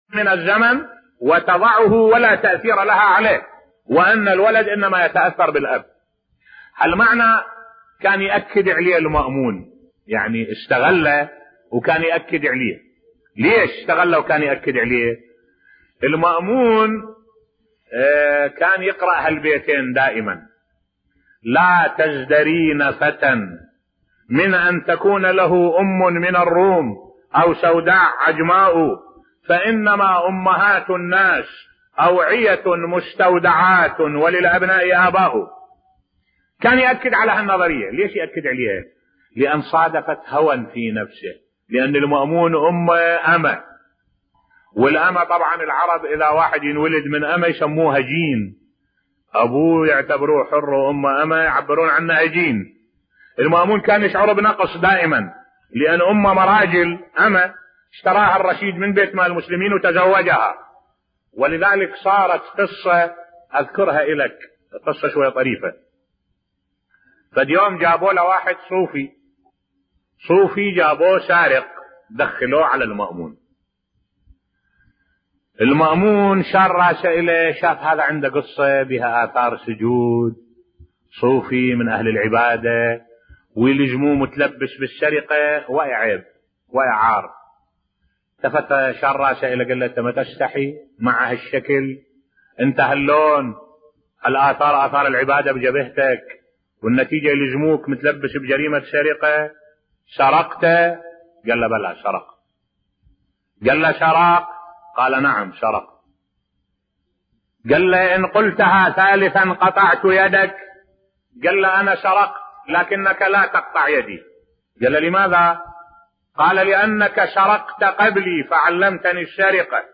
ملف صوتی السبب الرئيسي في حقد المأمون على الرضا (ع) بصوت الشيخ الدكتور أحمد الوائلي